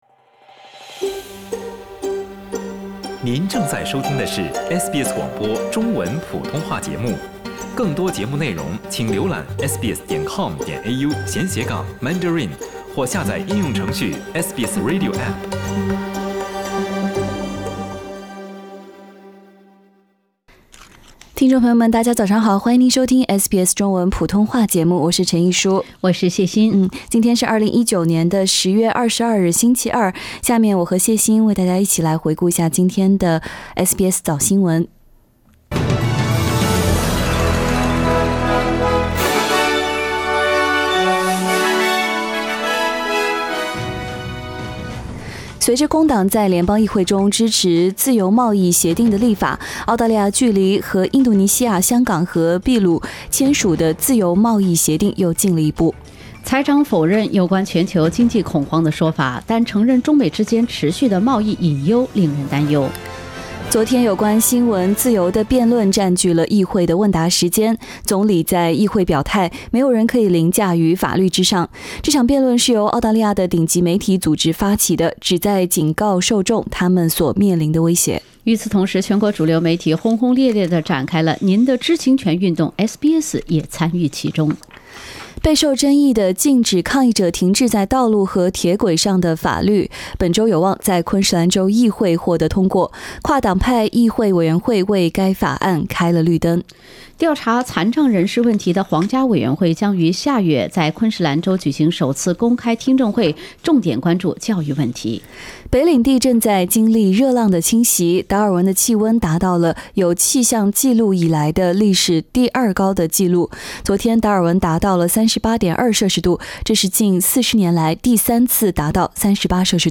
SBS早新闻（10月22日）